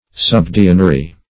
Subdeanery \Sub*dean"er*y\, n. Office or rank of subdean.